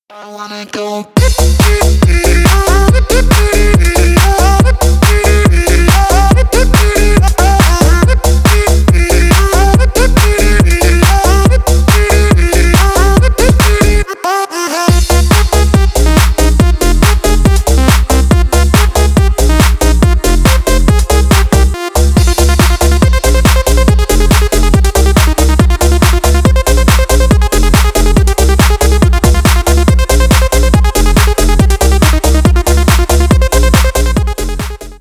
• Качество: 320 kbps, Stereo
Танцевальные
клубные
без слов